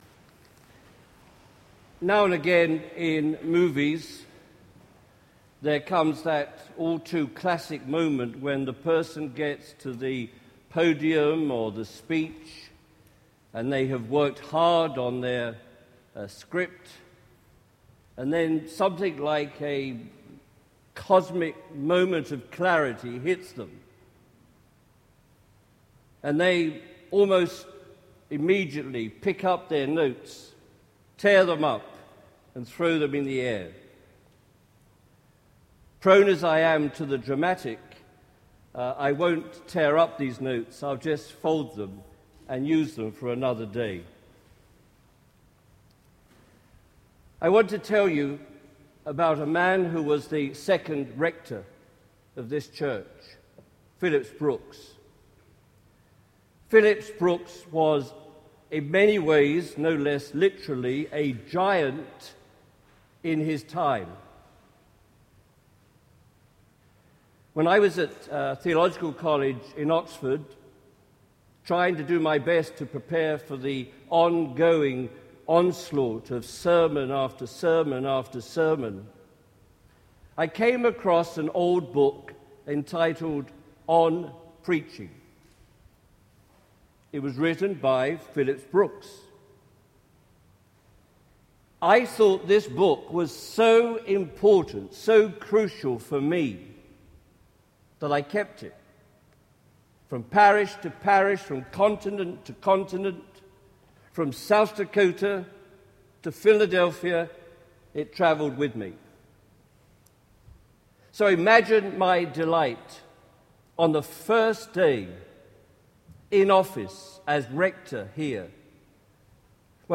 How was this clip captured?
This sermon was preached on Christmas Eve at the glorious Church of the Holy Trinity, Rittenhouse Square, Philadelphia. There is no text, you understand of course.